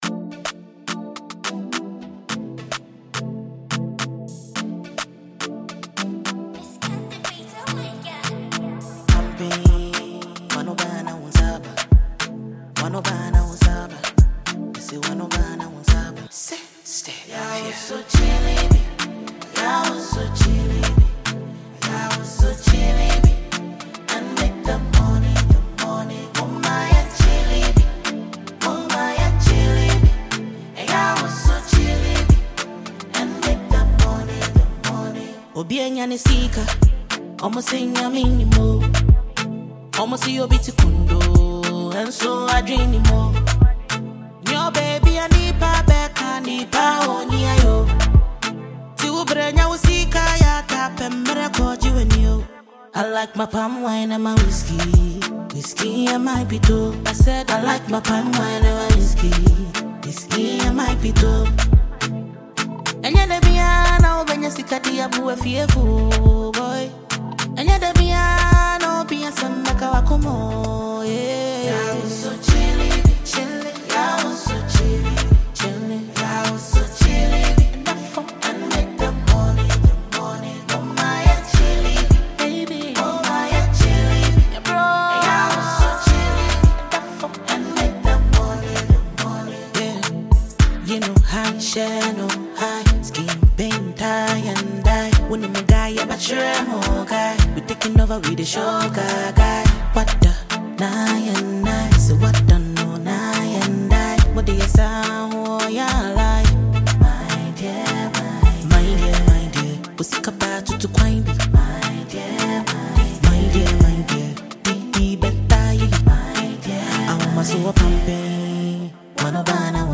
Ghanaian songstress
party track